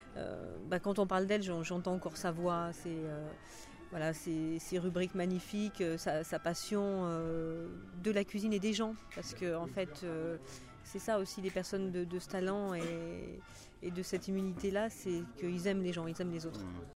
Un témoignage parmi d’autres
à Mougins en septembre 2010…